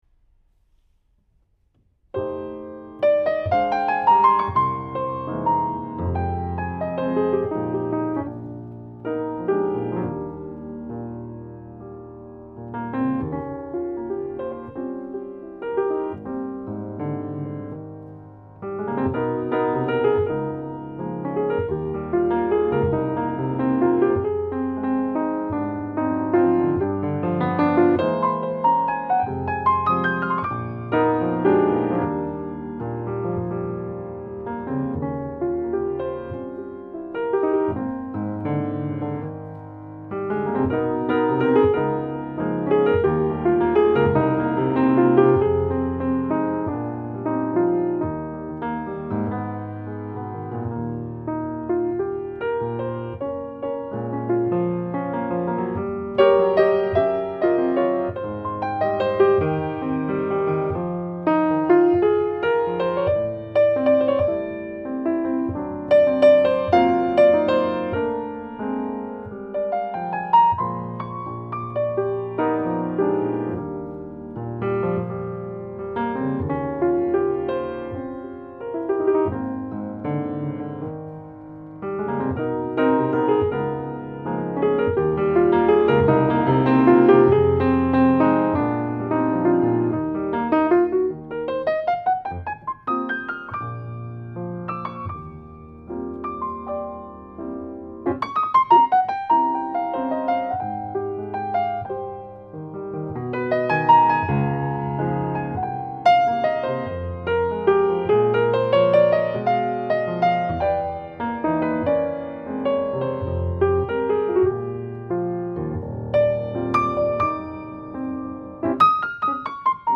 Lounge- und Barmusik